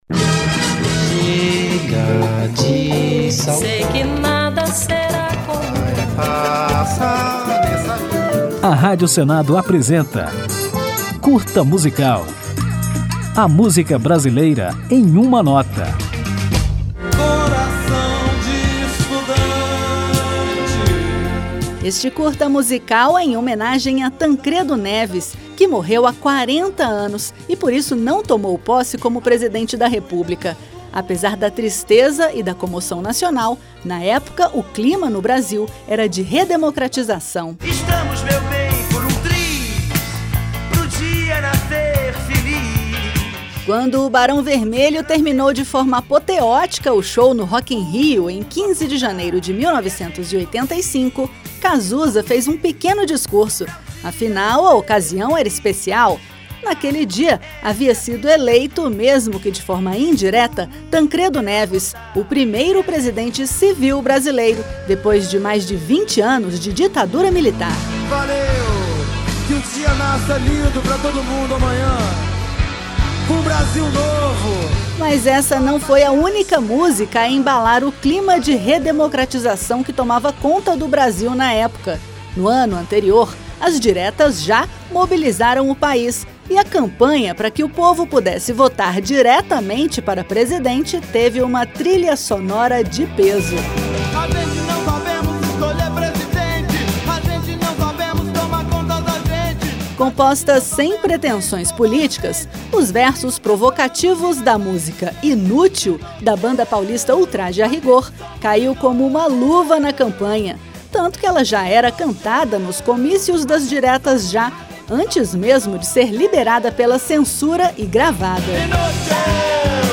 Na homenagem que o Curta Musical preparou à Tancredo, você vai conhecer os Hinos das Diretas Já, que são as músicas que embalaram esse período de redemocratização do Brasil. E, ao final do programa, ouviremos o maior desses hinos, a música Coração de Estudante, com Milton Nascimento, que acabou se tornando também a trilha sonora da morte de Tancredo Neves.